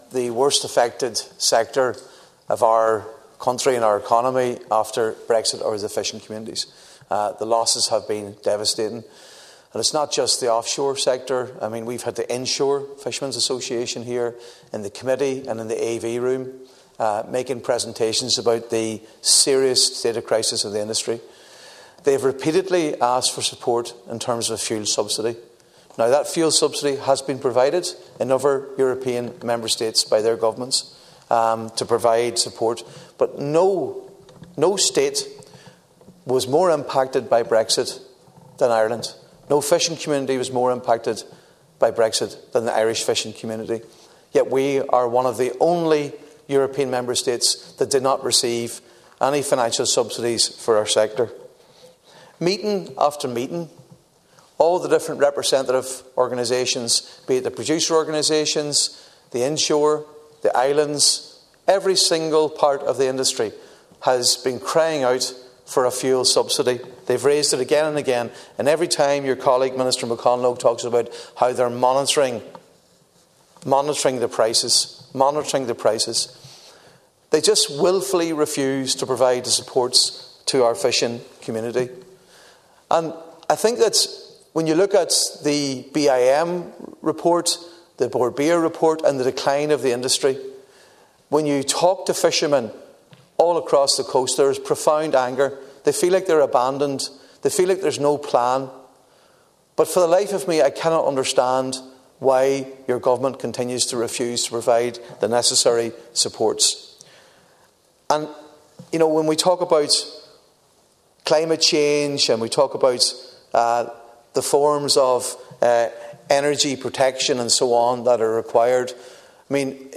The plea to Minister Michael McGrath came in the Dail from Deputy Padraig MacLochlainn, during the debate on a Sinn Fein motion calling for the scrapping of planned increases in the excise duty on fuel.